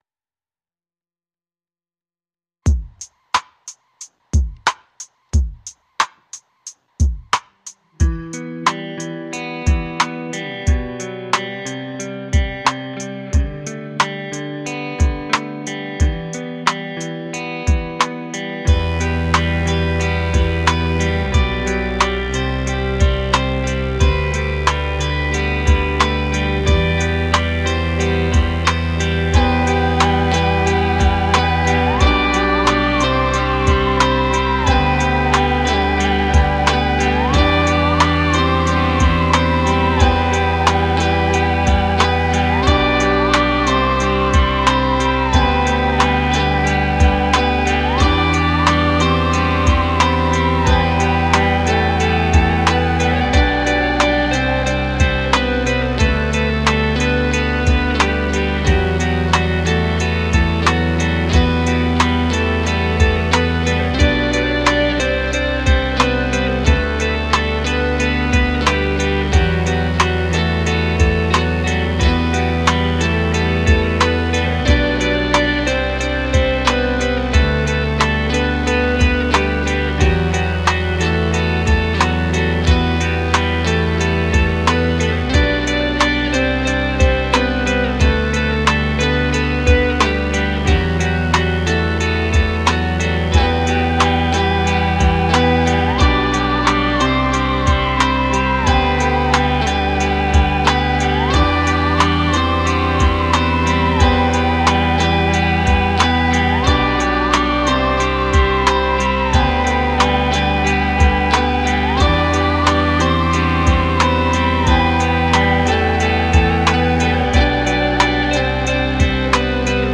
Tagged as: Alt Rock, Rock, Electro Rock, Trip-hop